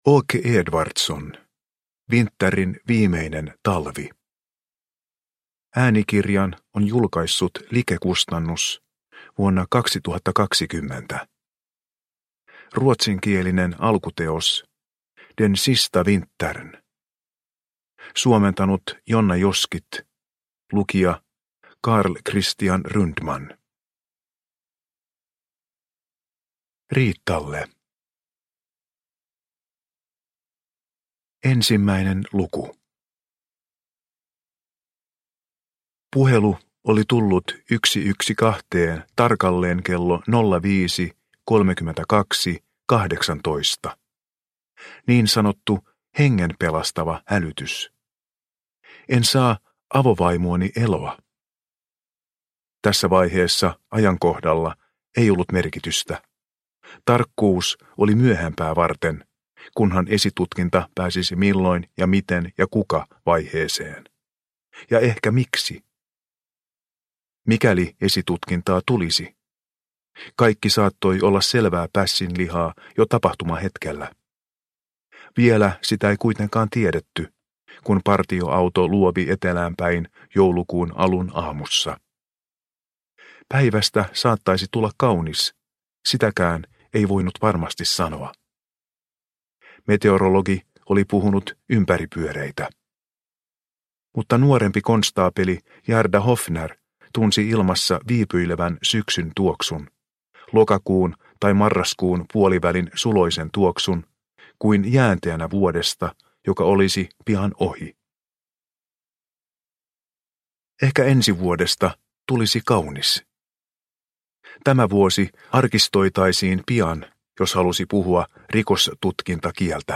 Winterin viimeinen talvi – Ljudbok – Laddas ner